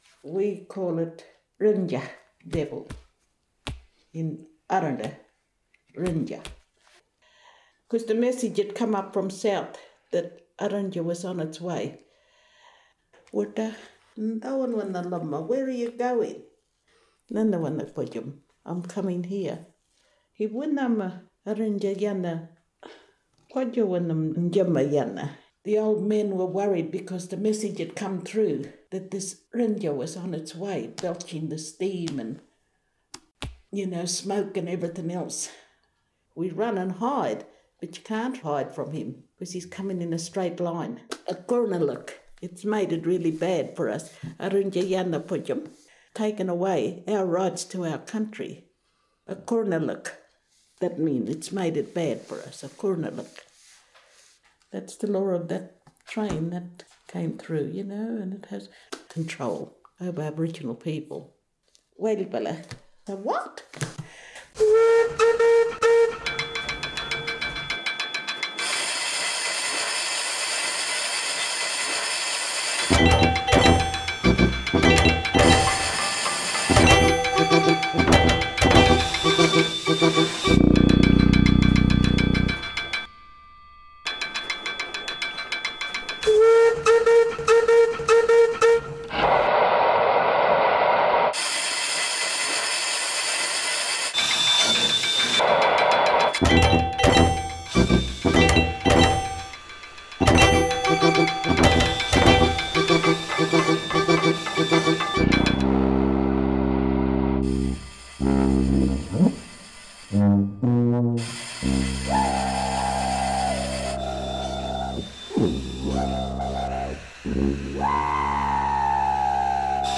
The Jon Rose Web Archive, Radio Violin - ghan stories, australia, railway, aboriginal history, steam, radiophonic, Ghan, ABC, alice springs